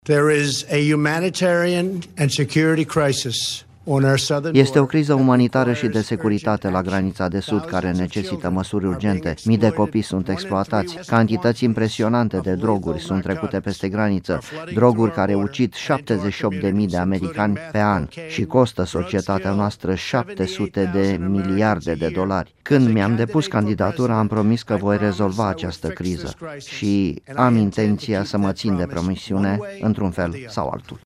Preşedintele american, Donald Trump, a propus aseară rivalilor săi democraţi un compromis pentru a pune capăt situaţiei actuale în care activitatea guvernului este suspendată parţial de peste o lună. Într-un discurs televizat, liderul american a spus că dacă democraţii vor accepta finanţarea zidului anti-imigraţie de la frontiera cu Mexicul, va aproba rămânerea în Statele Unite a persoanele care au intrat ilegal în ţară în tinereţe, aşa-numiţii „Visători”.